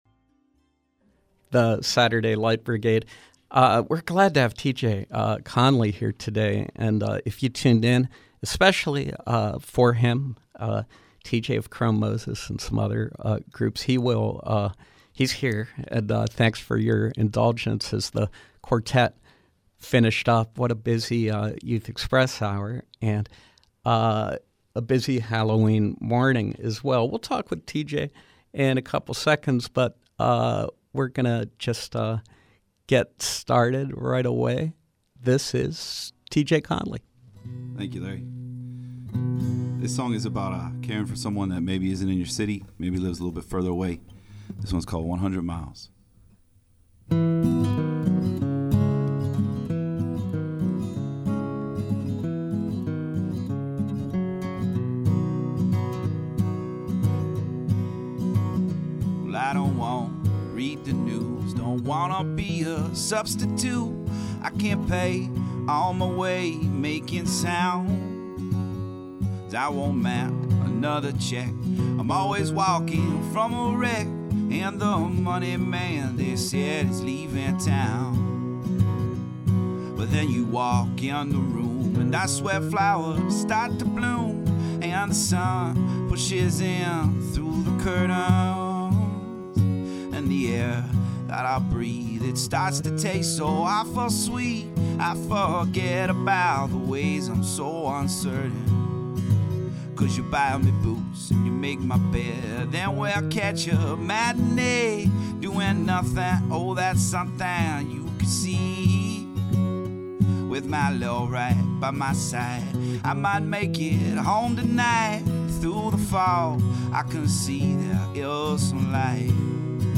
Songwriter and guitarist